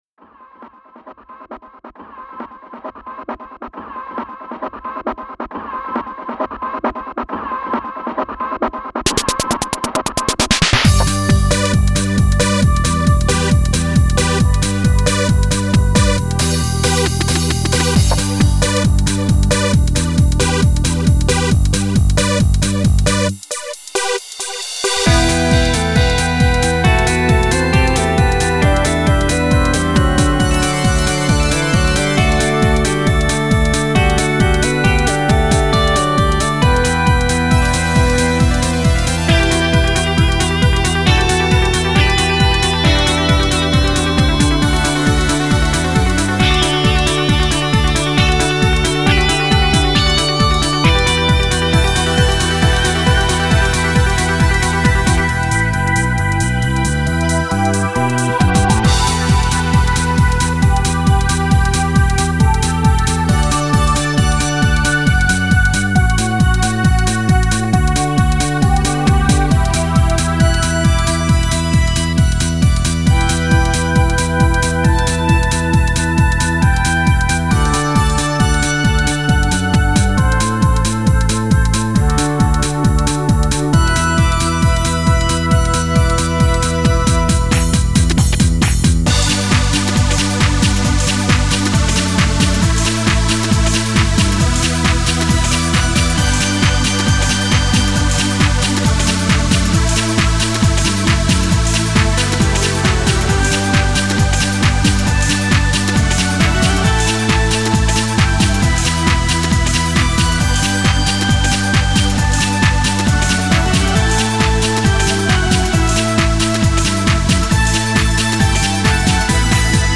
Ηλεκτρική Κιθάρα στο 5